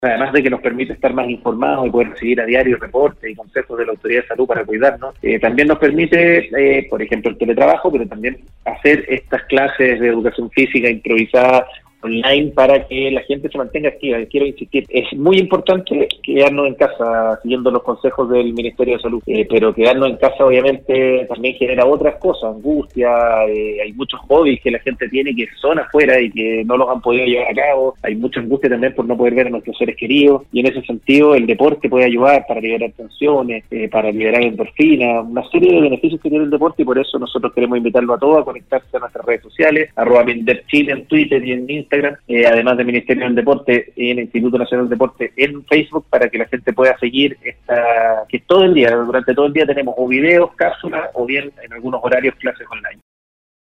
La mañana de este viernes, Andrés Otero, subsecretario del Deporte, sostuvo un contacto telefónico en el programa Al Día de Nostálgica, destacó la importancia del deporte como un beneficio para la salud, el espíritu, y en definitiva, para el bienestar de las personas.